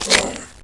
Heroes3_-_Skeleton_Warrior_-_HurtSound.ogg